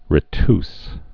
(rĭ-ts, -tys)